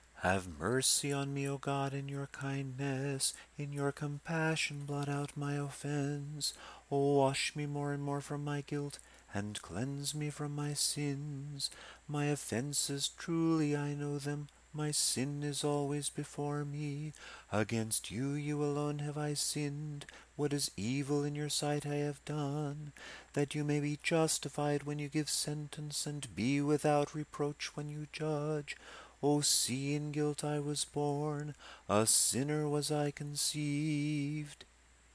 Recto tono
Recto tono (Latin for "straight" or "uniform" tone) is the simplest kind of church singing: the chanting or declamation of a text on a single pitch (called the "reciting pitch"), with merely the simplest ornamentation.
You can use both ornaments together: some lines start with an ascending perfect fourth to an accent (particularly one you wish to emphasize), with an occasional major second on a word or syllable in the middle of a line that you wish to stress.
Psalm_50_recto_tono.mp3